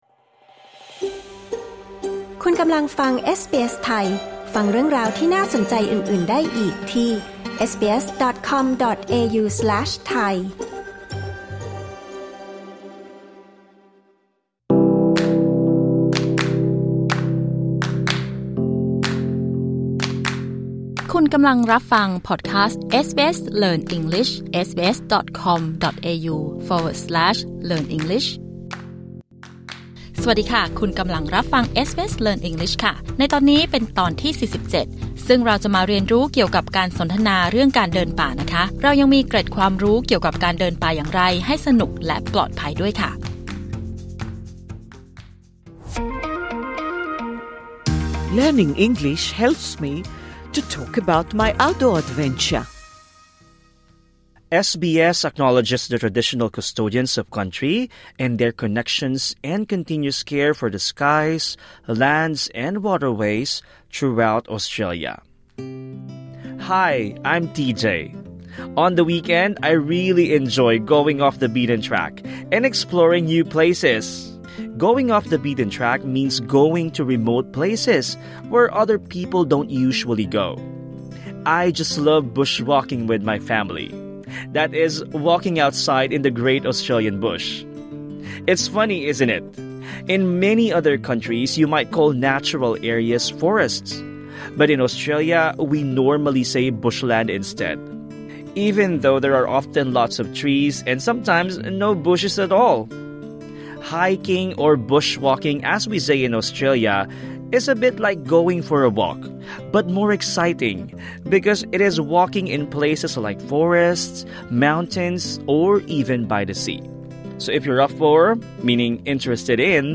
This lesson suits advanced learners.